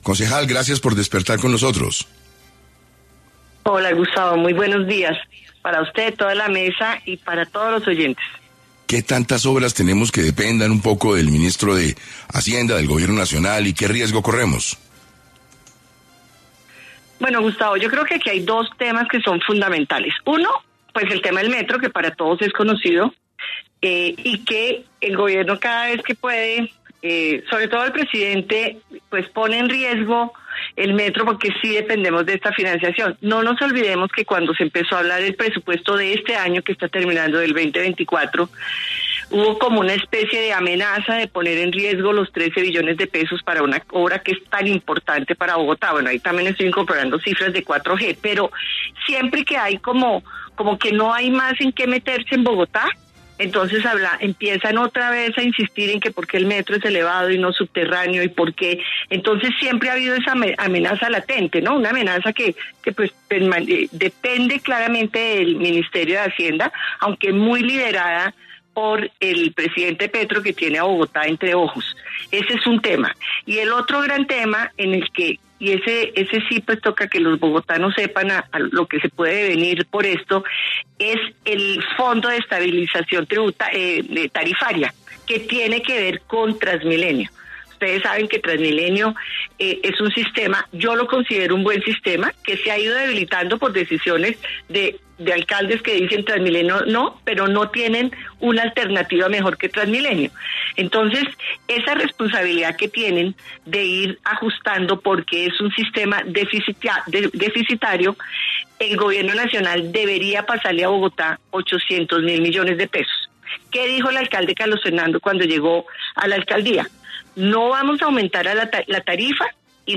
En 6AM de Caracol Radio estuvo Sandra Forero, concejal de Bogotá, quien habló sobre lo que pasará con la financiación de las obras de Bogotá tras la salida de Ricardo Bonilla del Ministerio de Hacienda y la cantidad de obras que financia el Gobierno en la capital.
Sandra Forero, concejal de Bogotá